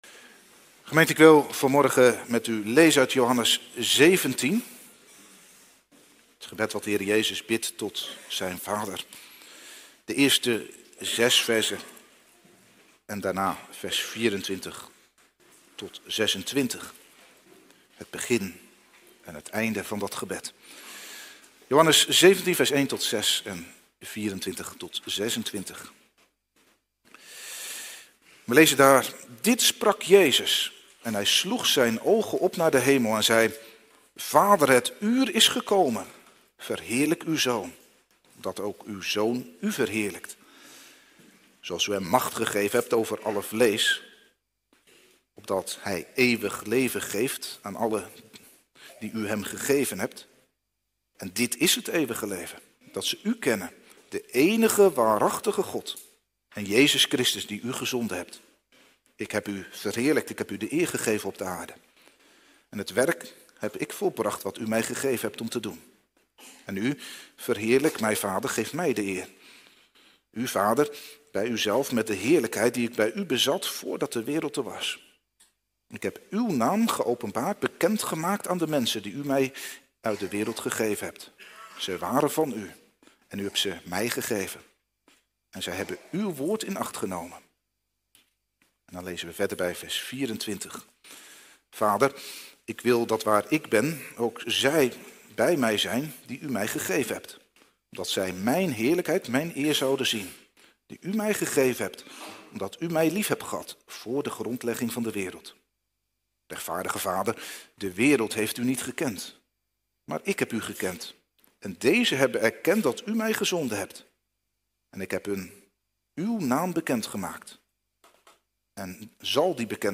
Uw Naam worde geheiligd Preek